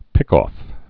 (pĭkôf, -ŏf)